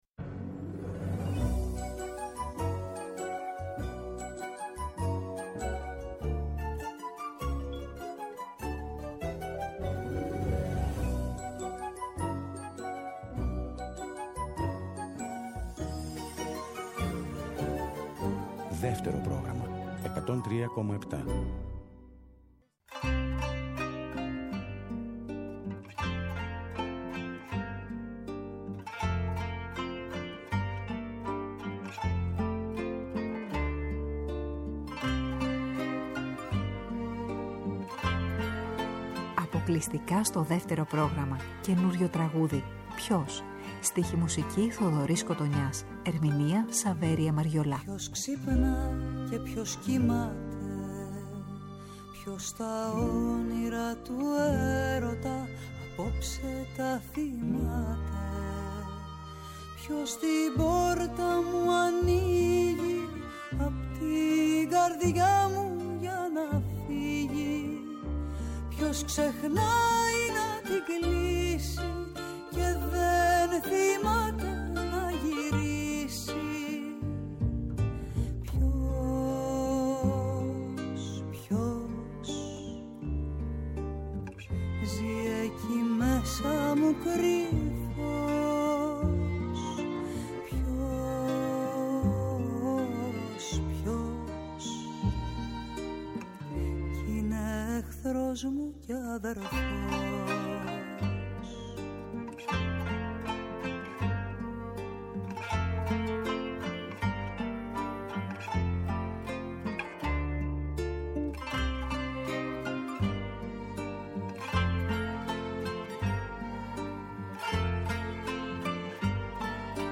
ήταν φιλοξενούμενος στο στούντιο του Δευτέρου Προγράμματος
Συνεντεύξεις